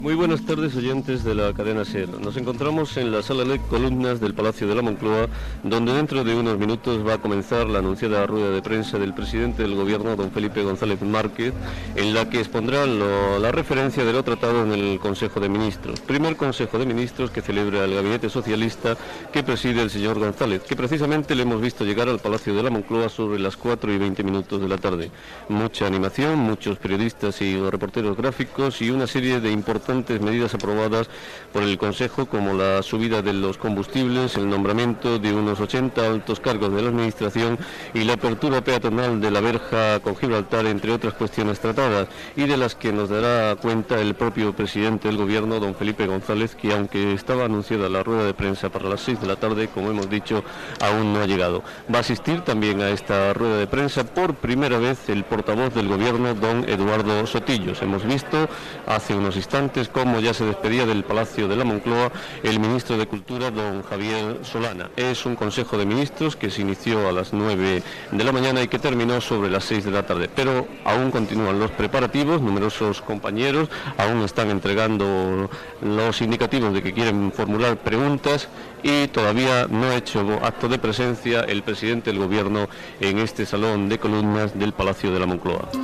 Transmissió des del Palacio de la Moncloa de la roda de premsa després del primer Consell de Ministres presidit per Felipe González.
Informatiu